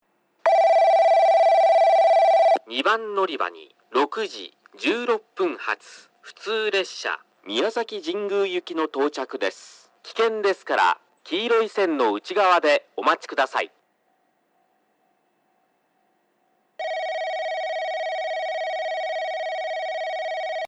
放送はJACROS簡易詳細型で、接近ベルが鳴ります。
スピーカーはFPS平面波です。なお放送の音割れが激しいですがこれは元からで、夜間音量の方が綺麗に聞こえます。
2番のりば接近放送（宮崎神宮行き）